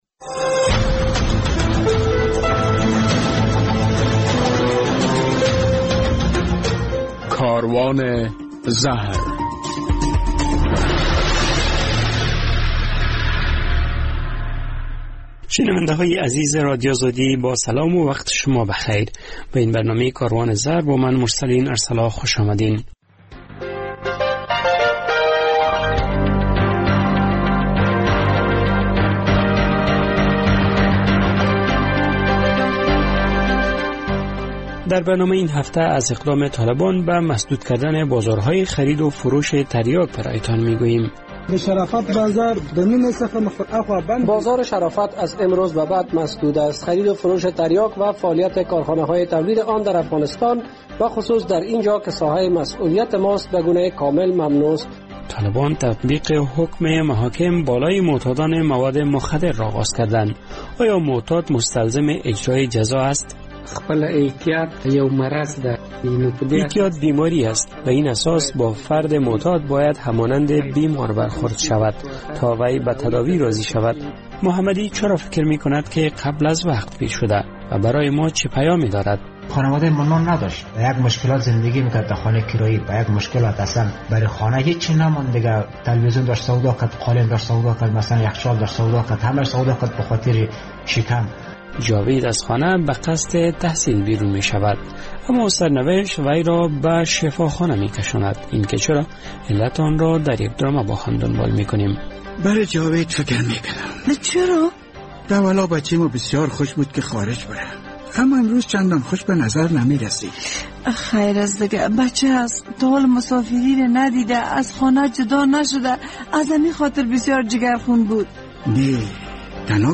در برنامه این هفته کاروان زهر می شنوید که طالبان پس از سه سال حاکمیت بر افغانستان حال تصمیم گرفته اند که بازارهای فروش تریاک را مسدود کنند. در یک گزارش از اجرای احکام جزایی توسط محاکم حکومت طالبان بر معتادان برای تان می گوییم. در ادامه برنامه هم خاطره معتاد و درامه برنامه را خواهید شنید.